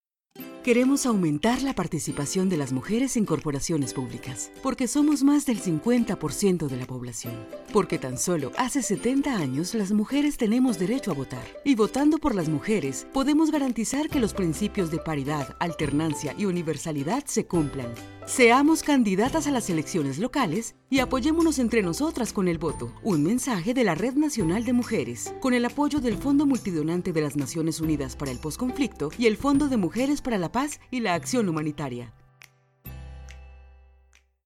VO IN SPANISH
Home recording studio. Professional Rhode microphones for excellent quality.